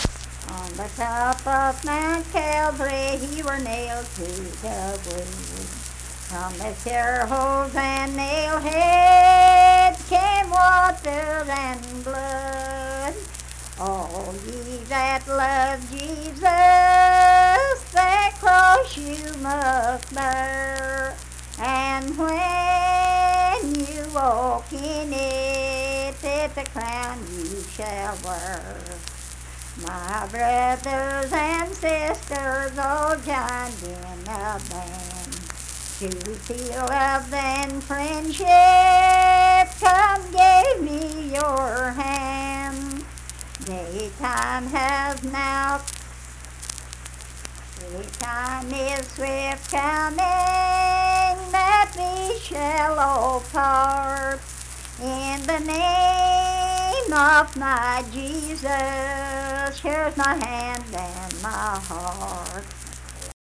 Unaccompanied vocal music performance
Verse-refrain 2d(4).
Hymns and Spiritual Music
Voice (sung)